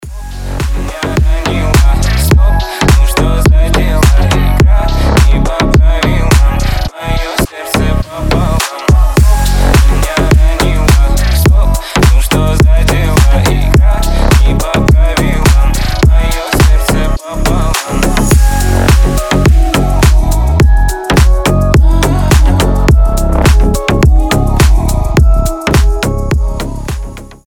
• Качество: 320, Stereo
мужской голос
мелодичные
Club House
басы
ремиксы